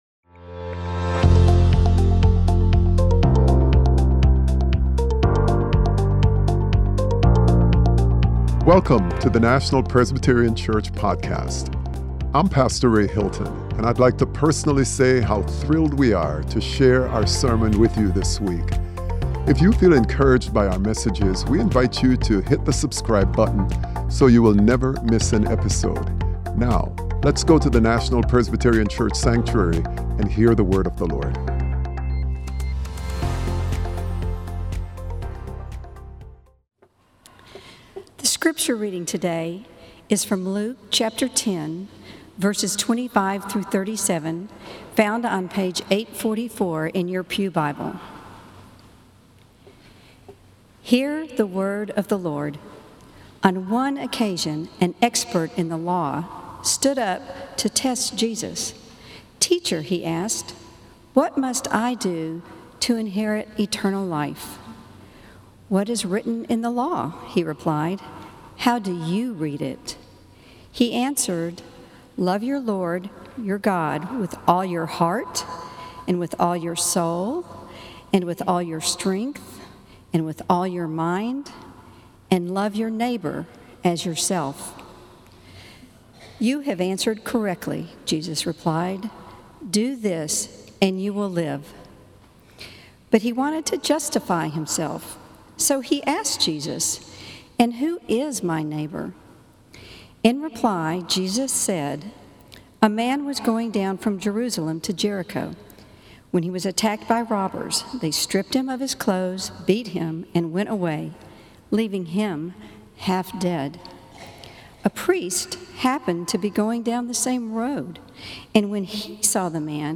Sermon: Mercy in Action - The Church's Call to Healing - National Presbyterian Church